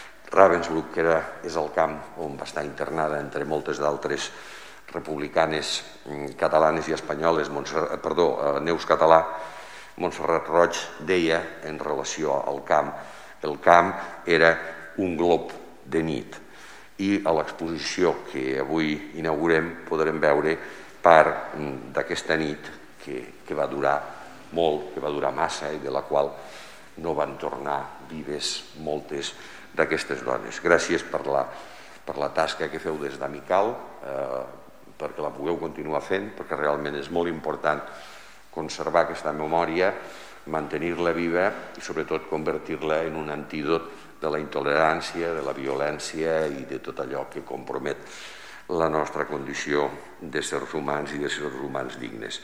tall-de-veu-de-lalcalde-miquel-pueyo-sobre-el-conveni-que-paeria-i-amical-mauthausen-han-renovat